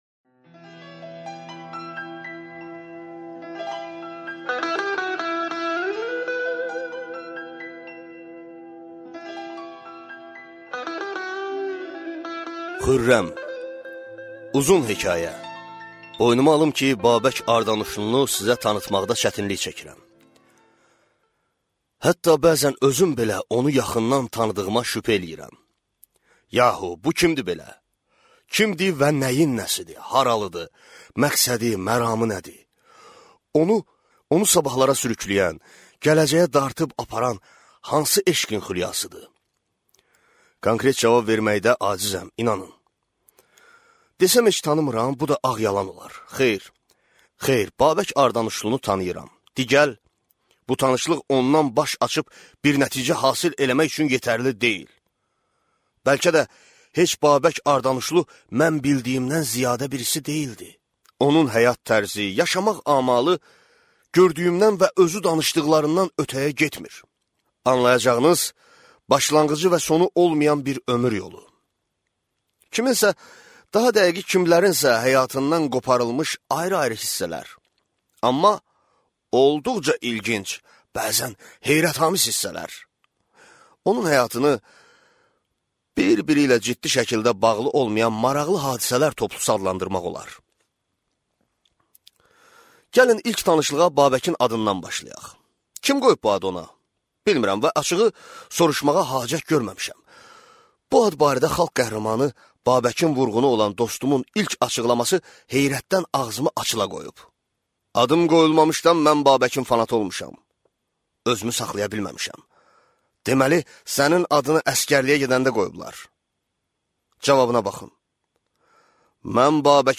Аудиокнига Hürrəm | Библиотека аудиокниг